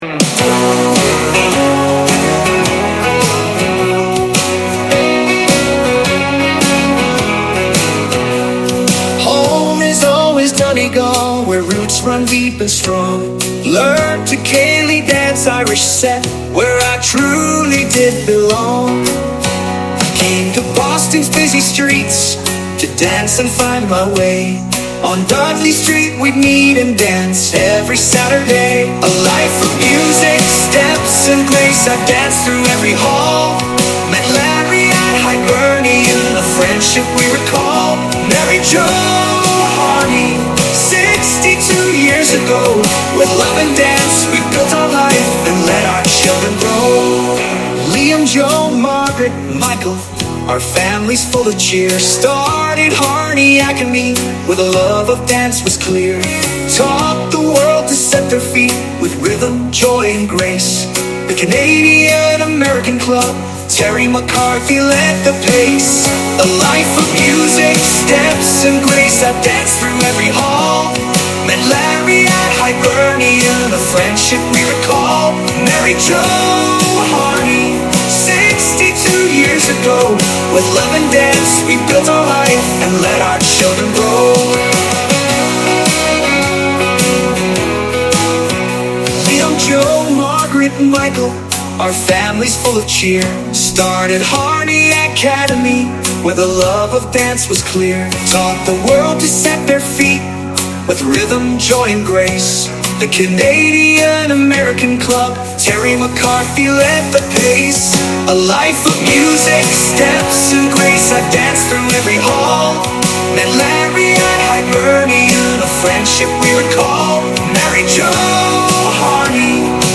a tribute song